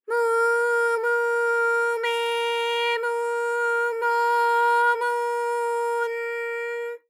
ALYS-DB-001-JPN - First Japanese UTAU vocal library of ALYS.
mu_mu_me_mu_mo_mu_n_m.wav